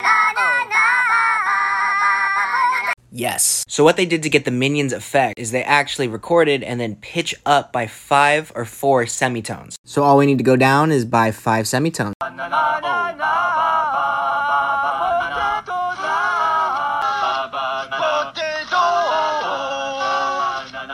Making the minions sound like humans!